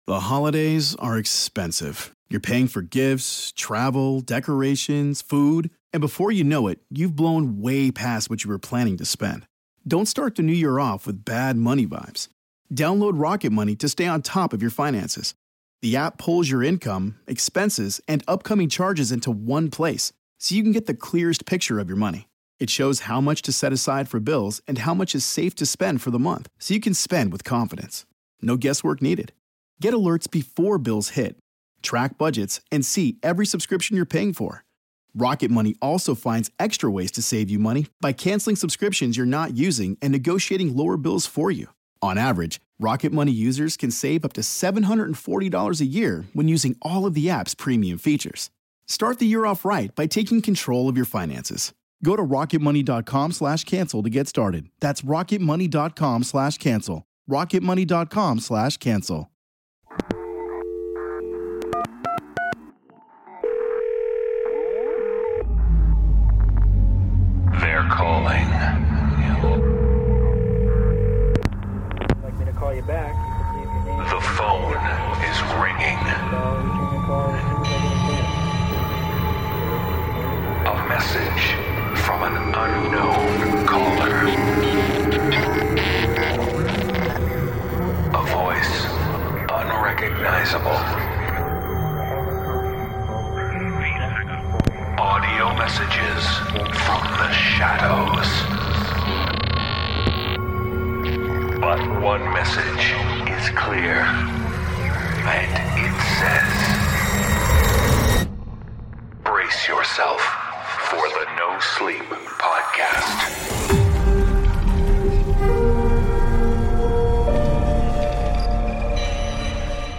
The voices are calling with tales of trapped torment.